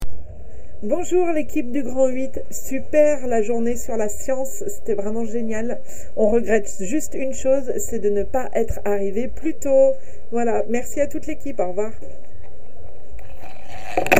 Cabine de témoignages
Témoignage du 27 septembre 2025 à 18h58